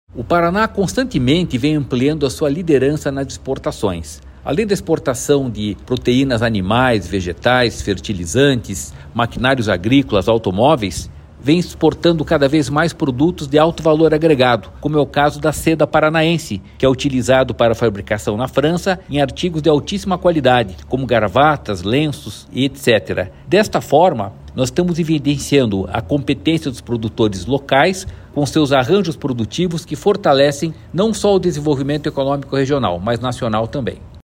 Sonora do diretor-presidente do Ipardes, Jorge Callado, sobre a liderança do Paraná em exportações em diversos segmentos